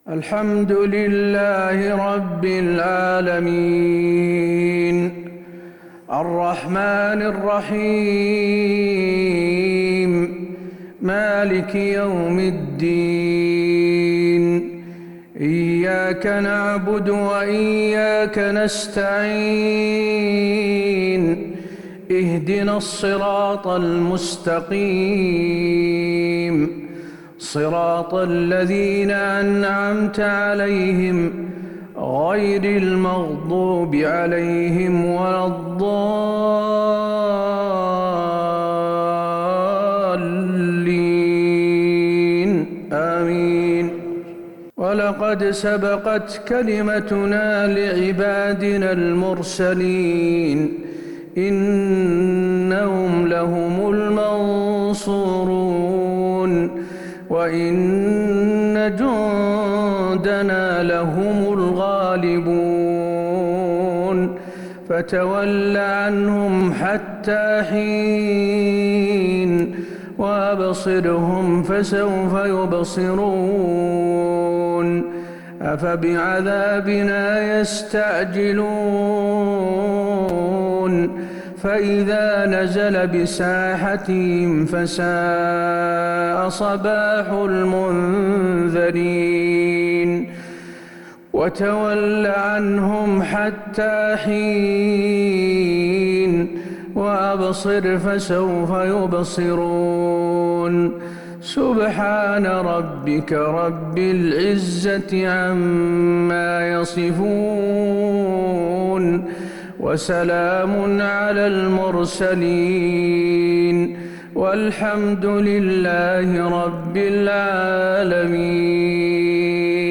صلاة العشاء للشيخ حسين آل الشيخ 2 ذو الحجة 1442 هـ
تلاوة من سورتي الصافات و المطففين.....